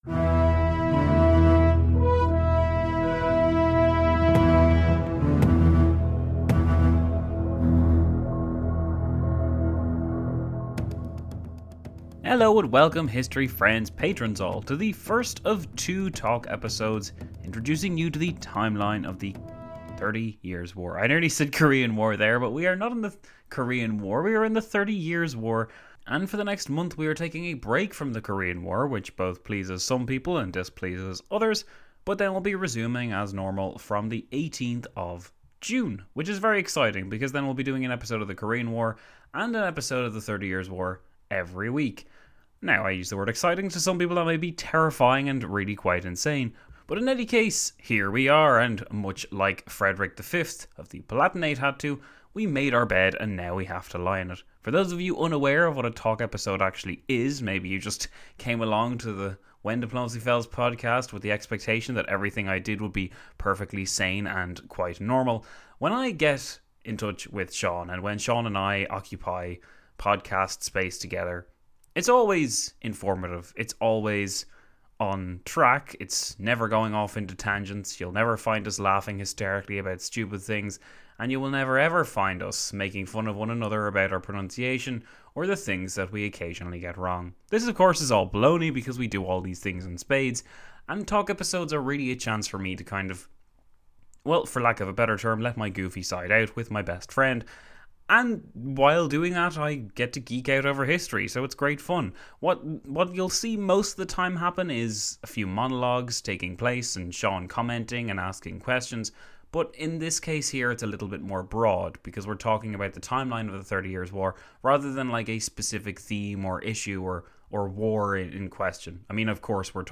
When Diplomacy Fails is 6 years old today, and to celebrate we're jumping right into the Thirty Years War once again, with another intro episode (2/5) this one looking at the timeline of the conflict up to the year 1635, but with a twist. I'm not by myself this time